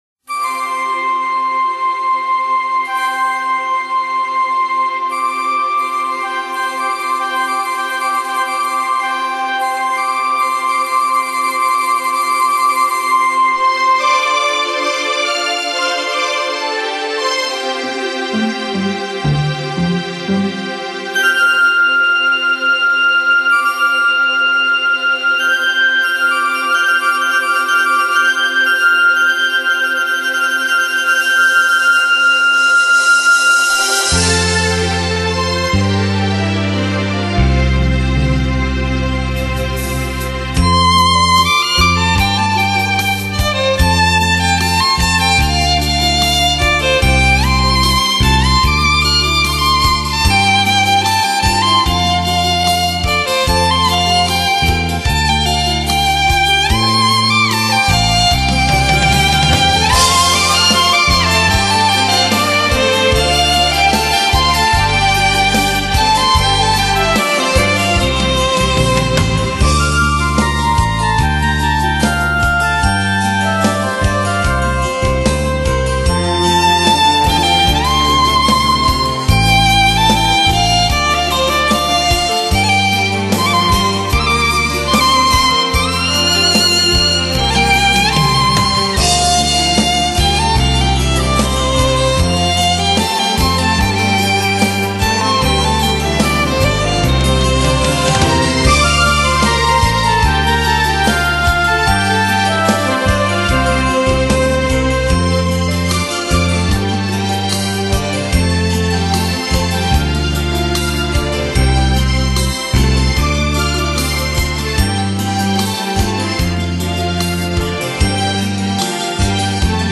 所属分类：音像电子产品 -> 乐曲 -> 舞曲
慢四